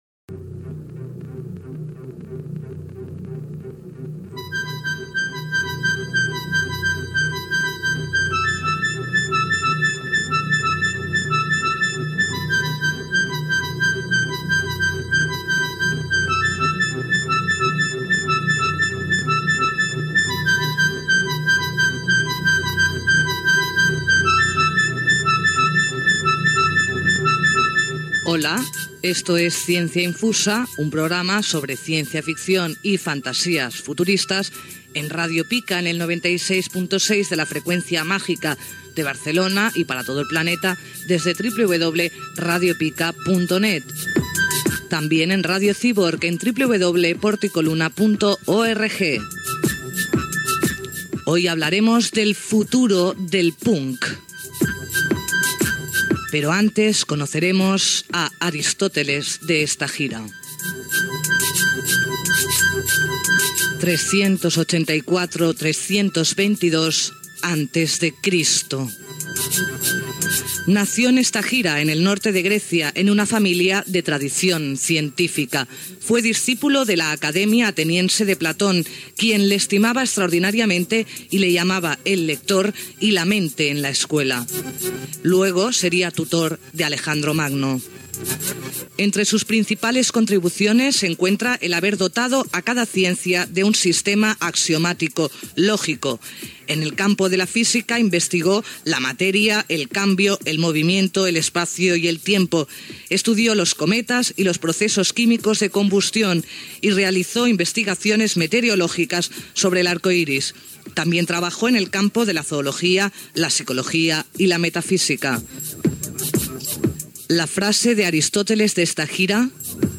Sintonia, identificació del programa, el filòsof Aristòtil, adreça electrònica del programa, tema musical, informació sobre una publicació punk i la xerrada feta en una llibreria. Entrevista sobre si té futur el punk
FM